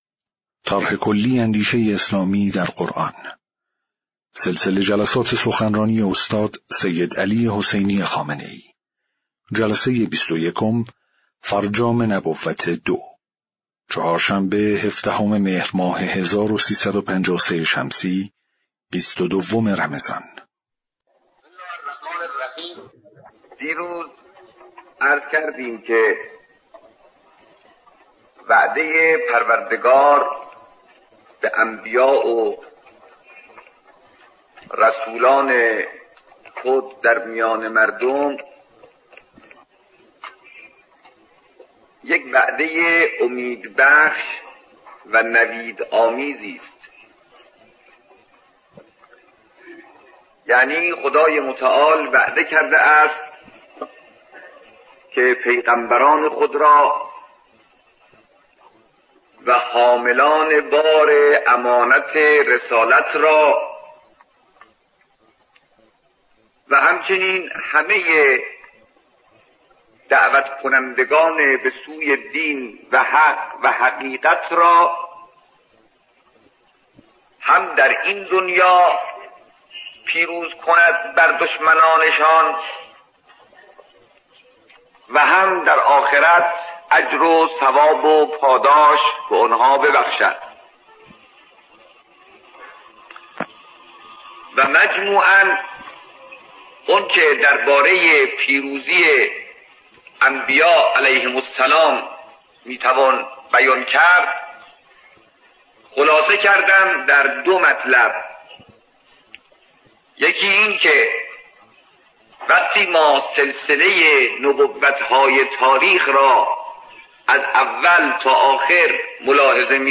صوت/ جلسه‌ بیست‌ویکم سخنرانی استاد سیدعلی‌ خامنه‌ای رمضان۱۳۵۳